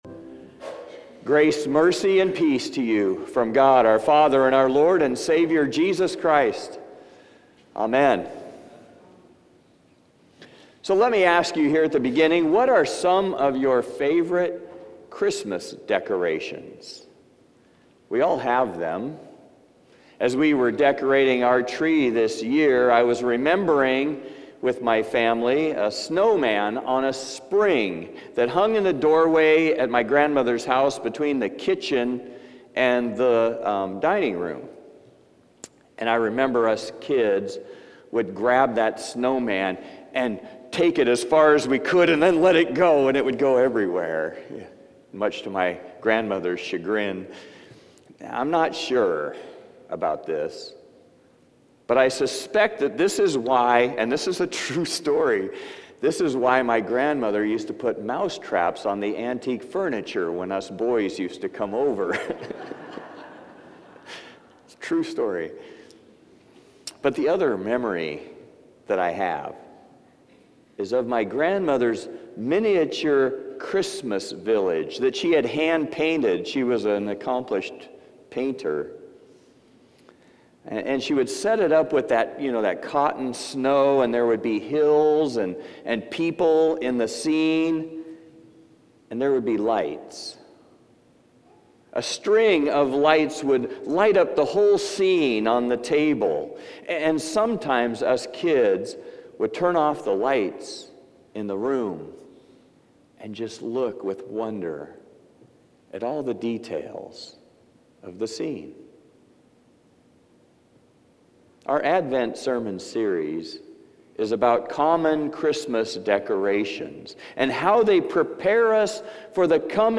Passage: John 1:1-9 Service Type: Traditional and Blended